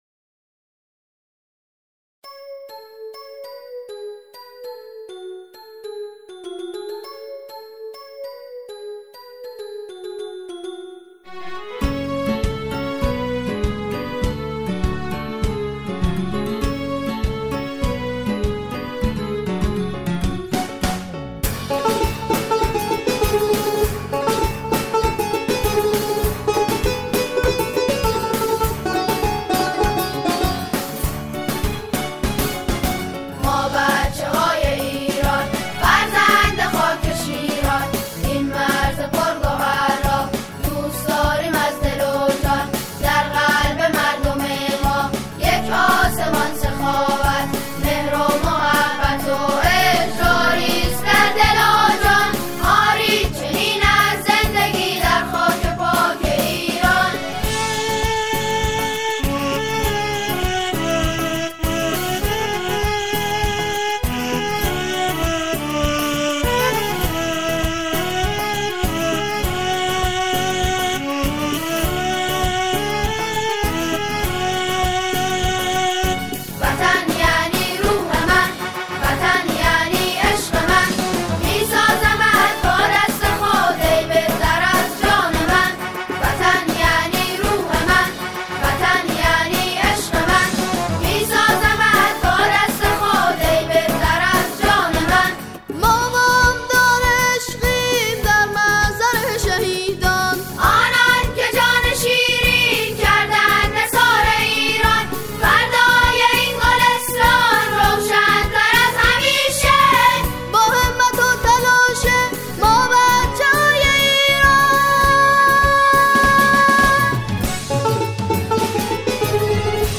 با نقش آفرینی پنج هزار نوجوان و جوان دانش آموز